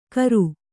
♪ karu